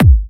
VEC3 Bassdrums Trance 57.wav